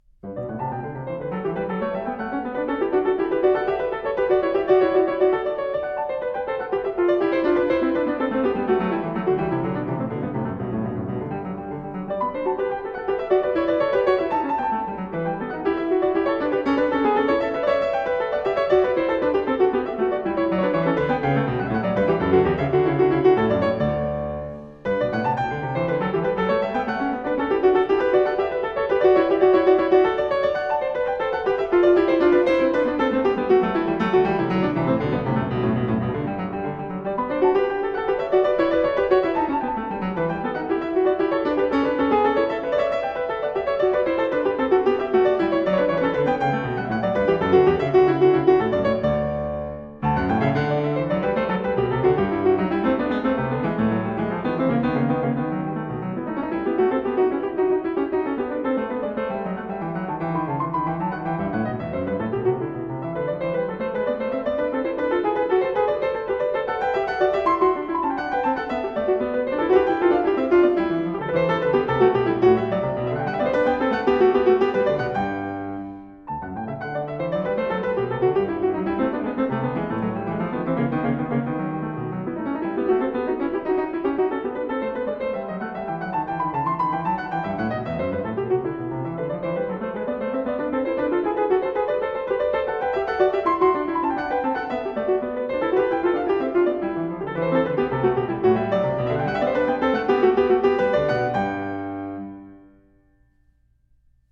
Kimiko Ishizaka - J.S. Bach- -Open- Goldberg Variations, BWV 988 (Piano) - 18 Variatio 17 a 2 Clav.